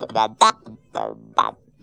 BEATVOICE3.wav